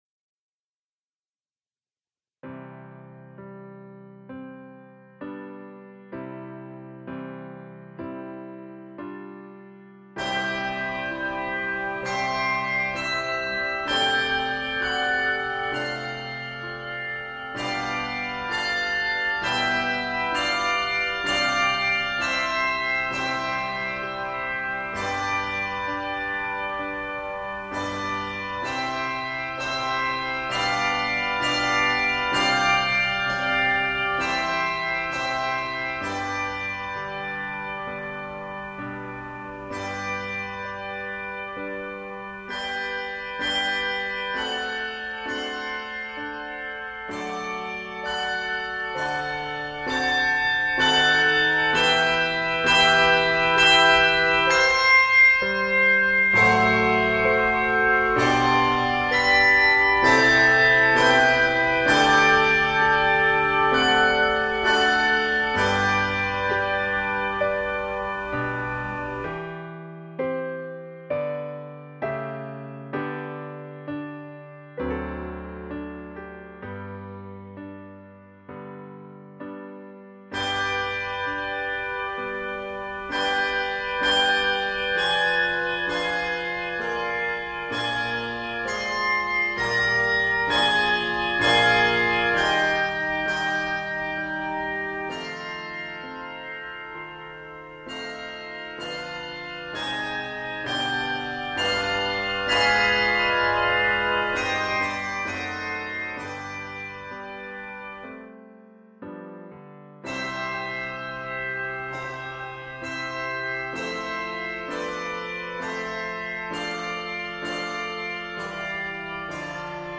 beautiful and flowing setting
Key of C Major.